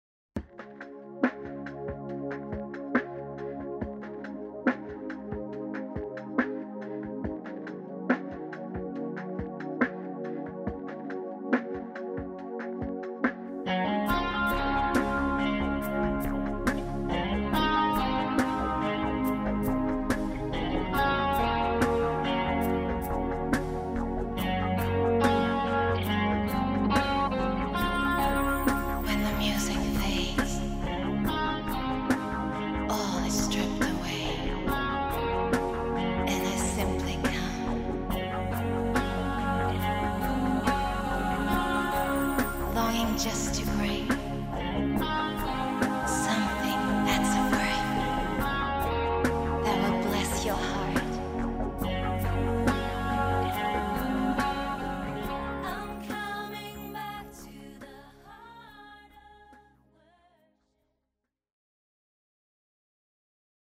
Это вступление.